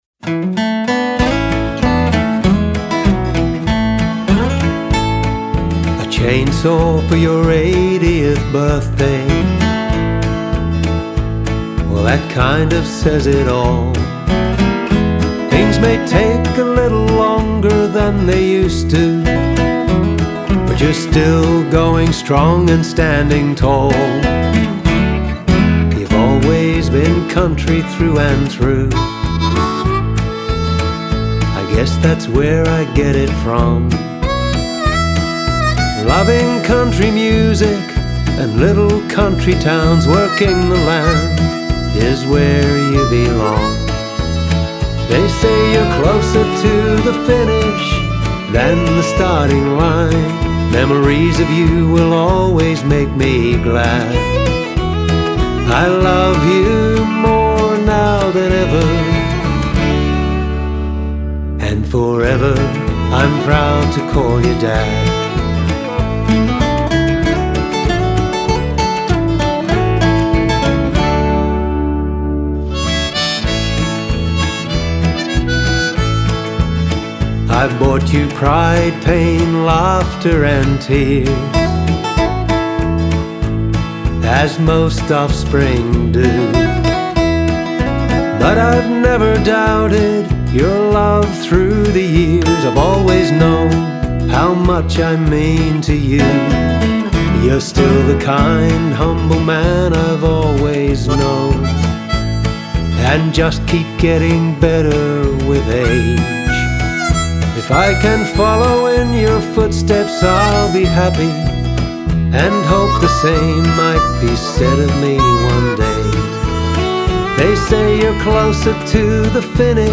With a beautiful, catchy, Bush Ballad feel